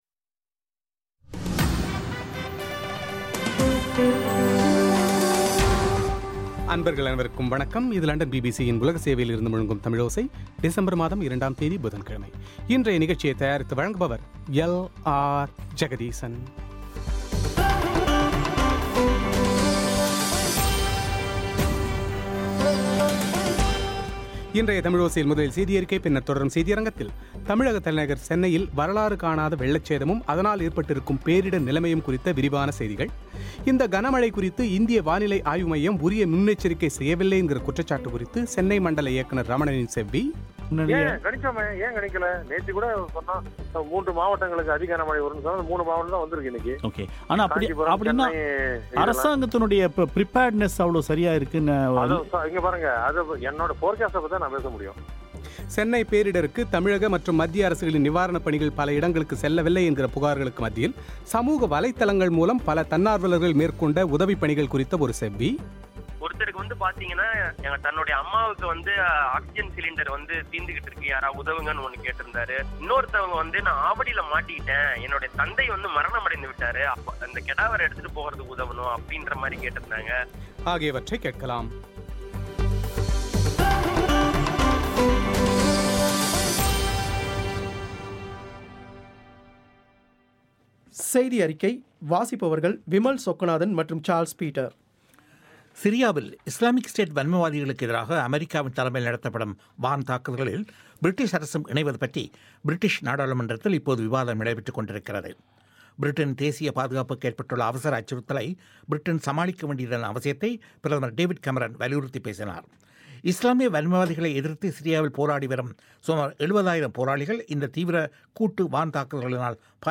தமிழக தலைநர் சென்னையில் வரலாறு காணாத வெள்ளச்சேதமும் அதனால் ஏற்பட்டிருக்கும் பேரிடர் நிலைமையும் குறித்த நேரடிச் செய்தித்தொகுப்பு;